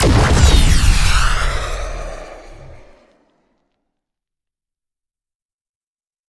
missilef.ogg